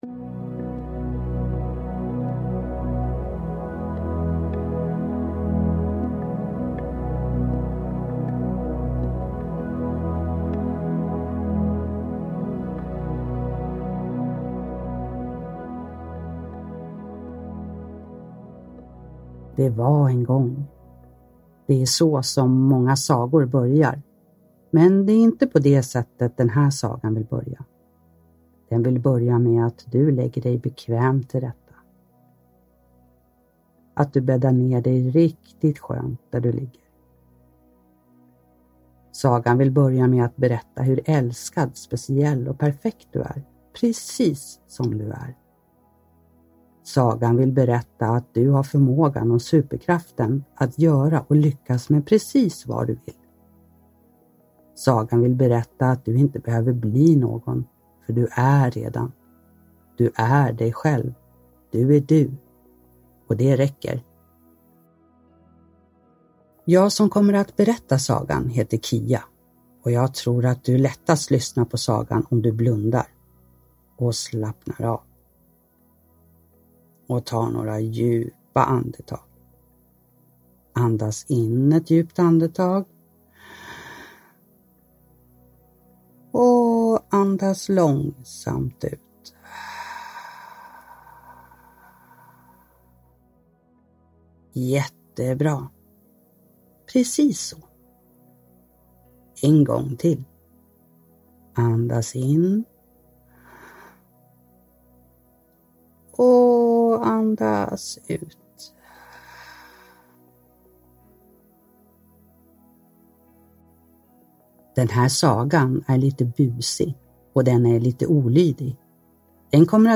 Vulkanen som inte ville vakna, en guidad godnattsaga – Ljudbok – Laddas ner